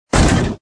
TrafficCollision_1.mp3